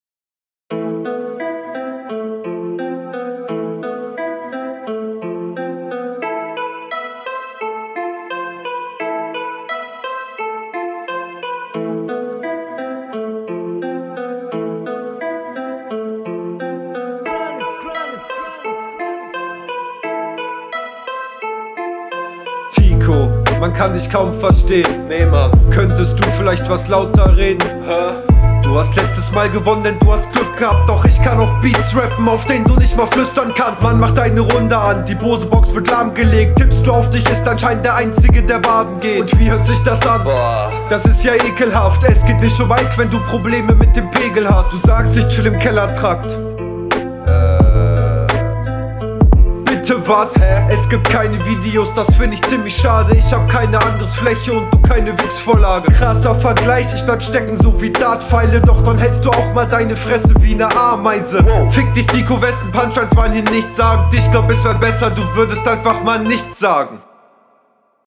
Was mich am meisten hieran gestört hat war dass man immer das Rauschen im Hintergrund …
Einstieg finde ich total überzogen stimmlich und flowlich sehr stockend. vor allem die ersten zwei …